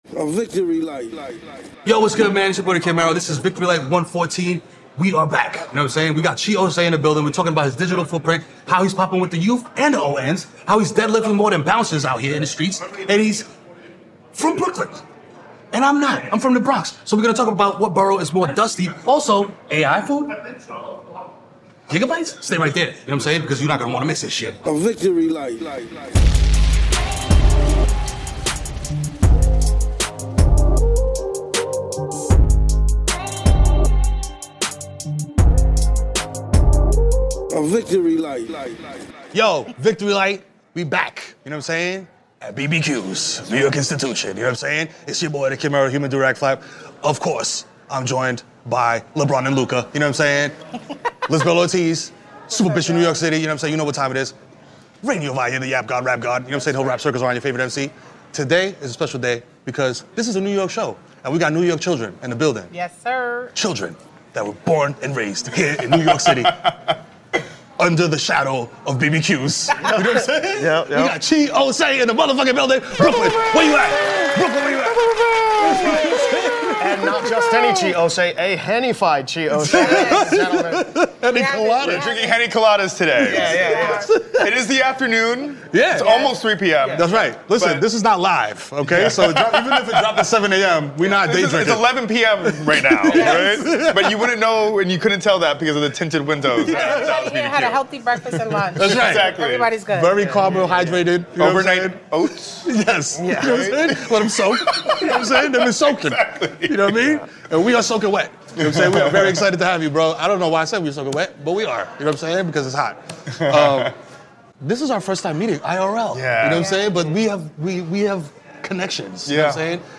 Click to listen, free! 161 episodes in the Comedy Interviews genre.